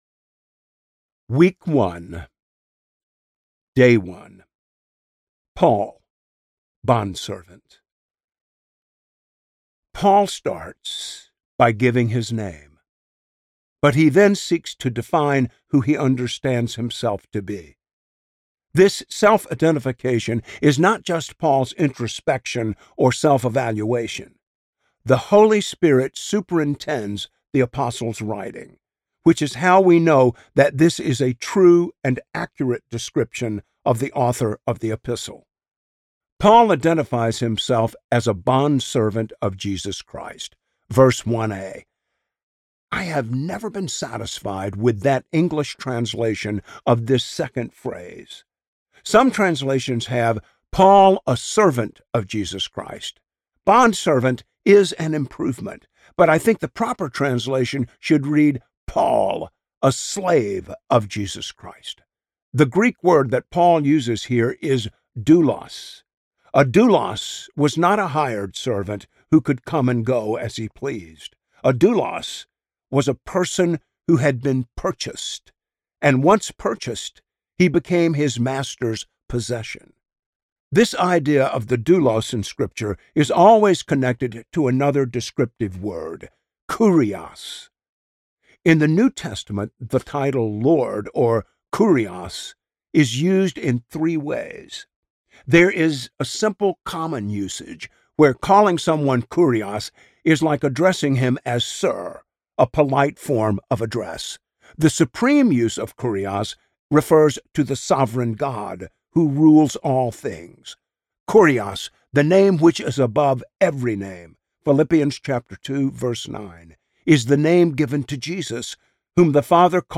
The Power of the Gospel: A Year in Romans: R.C. Sproul - Audiobook Download, Book | Ligonier Ministries Store